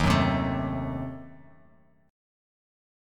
D#dim7 chord